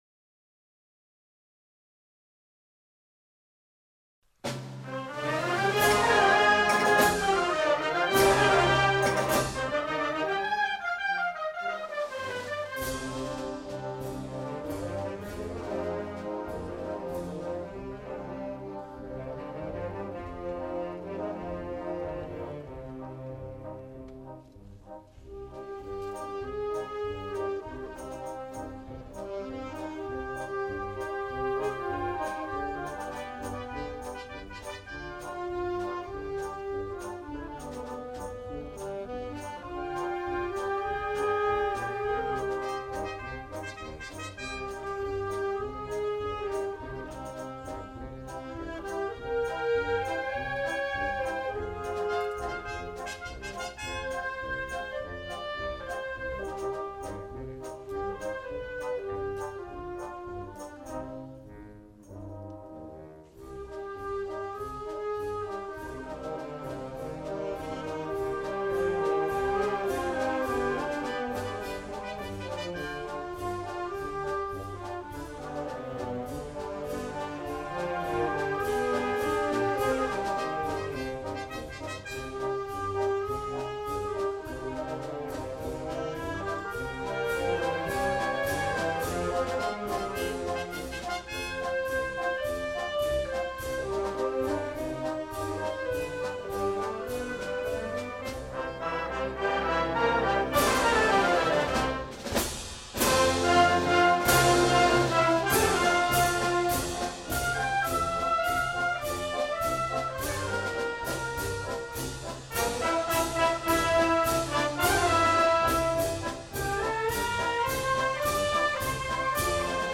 Himne
himno_doctor_ferran.mp3